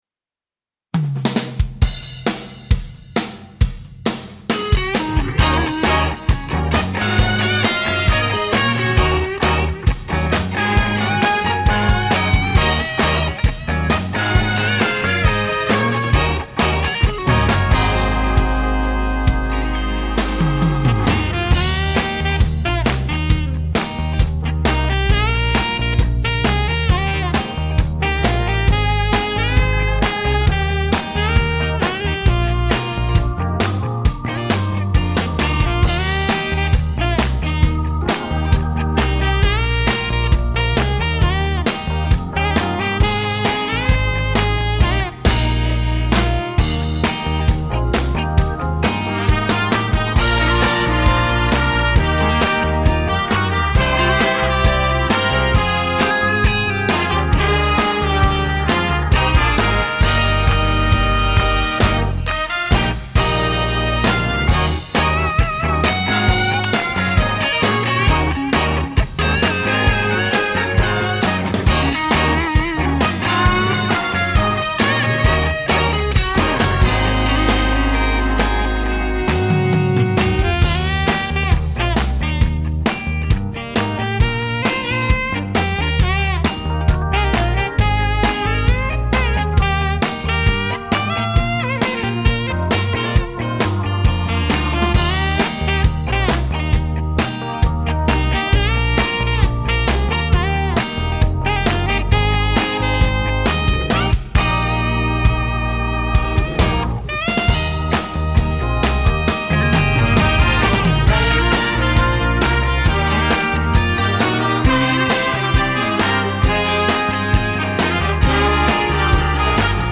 Trumpet 1 & 2
Trombone 1 & 2
Tenor Sax
Written out piano/keyboard
Written out electric bass